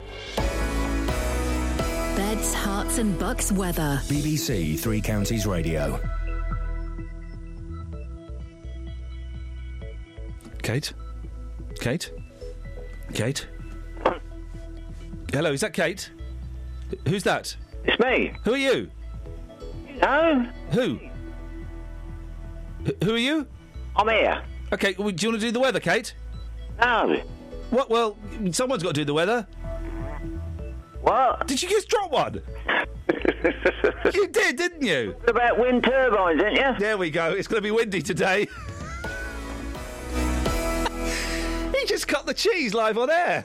Caller farts on the radio